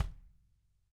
Index of /90_sSampleCDs/ILIO - Double Platinum Drums 2/Partition A/REMO KICK D